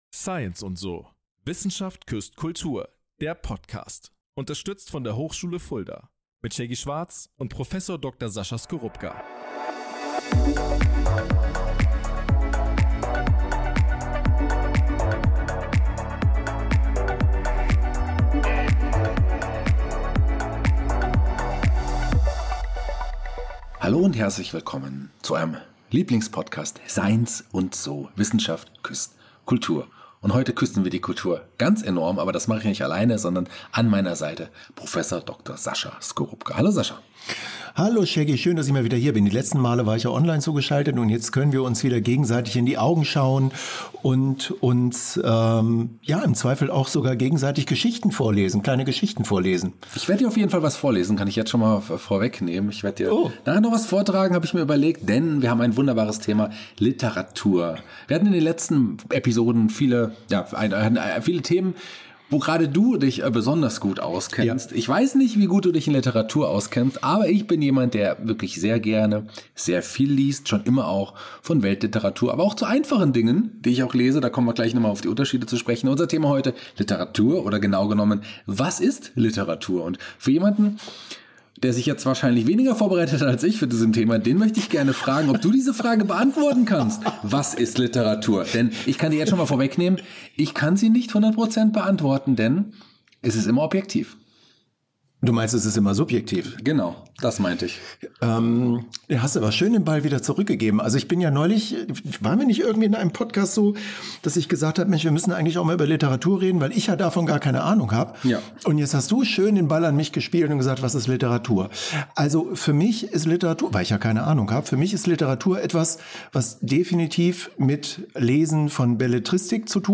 inklusive einer stimmungsvollen Lesung zum Abschluss.